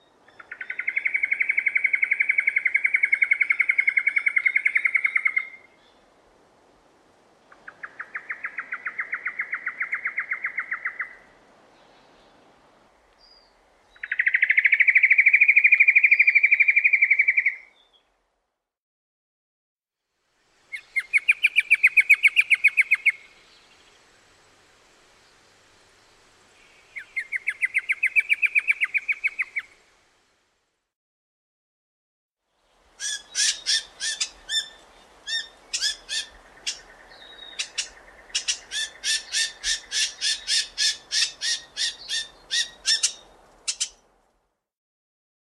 Meliphaga lewinii
Songs & Calls
lewins-honeyeaterNOM-web.mp3